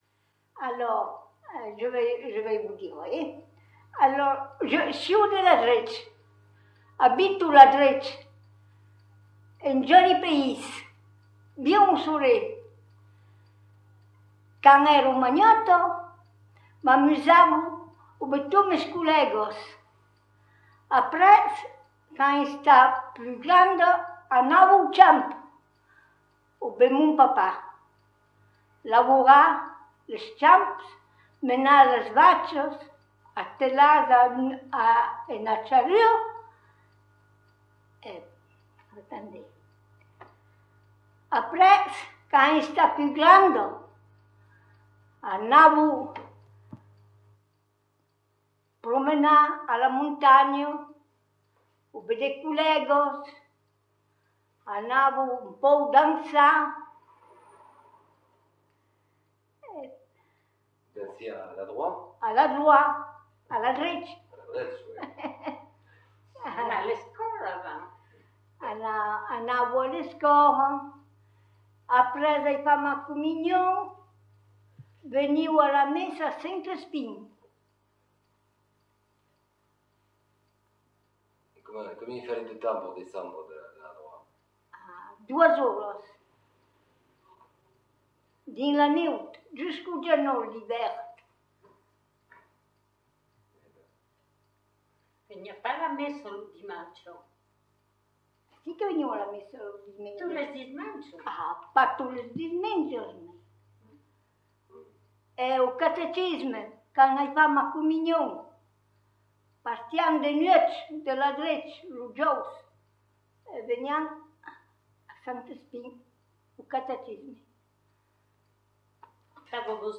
» Lo Centre d’Oralitat Aupenc de Gap, un ofici dau Consèlh General de 05, s’es urosament ocupat de numerizar les vièlhs enregistraments.